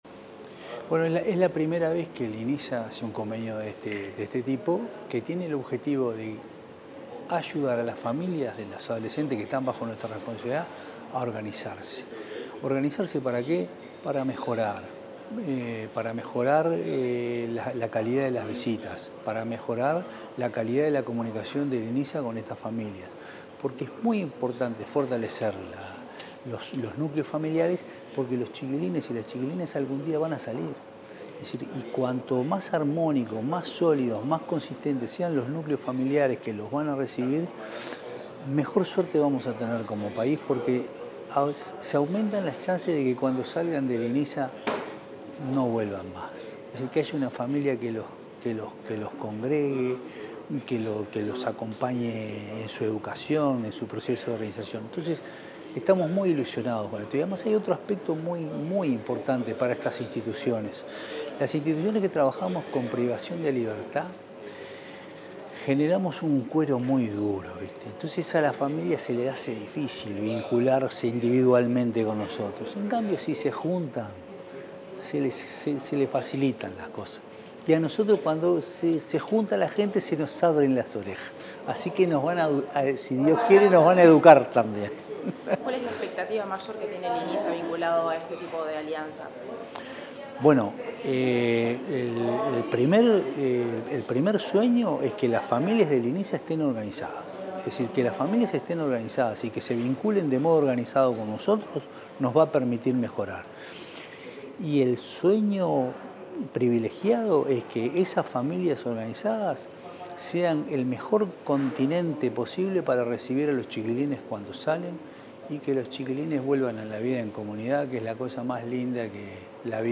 Declaraciones del presidente del Inisa, Jaime Saavedra
Declaraciones del presidente del Inisa, Jaime Saavedra 09/12/2025 Compartir Facebook X Copiar enlace WhatsApp LinkedIn En ocasión de la firma del convenio entre el Instituto Nacional de Inclusión Social Adolescente (Inisa) y la asociación civil Familias Presentes, el titular del organismo estatal, Jaime Saavedra, dialogó con la prensa.